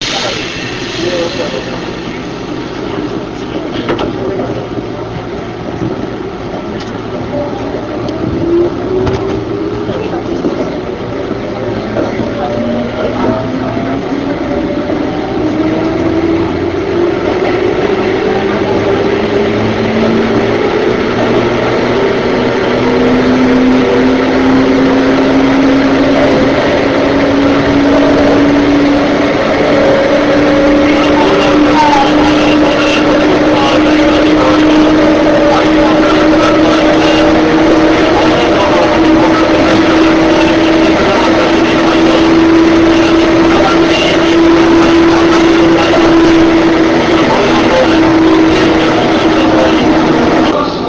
３０１系の音
３０１系加速音 木場→東陽町 RealAudio形式 104kb
１０３系の加速音とほぼ同じなのですが、音の聞こえがよいので設置しました。